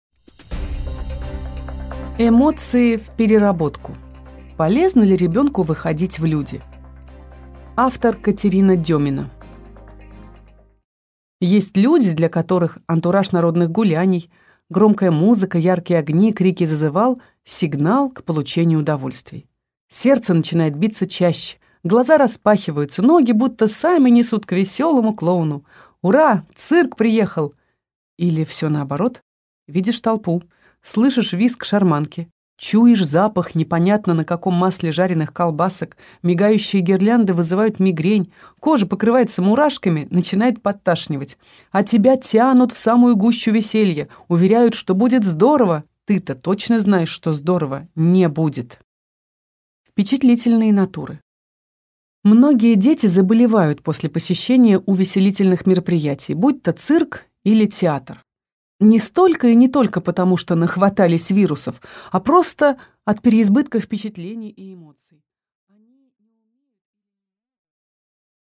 Аудиокнига Эмоции – в переработку | Библиотека аудиокниг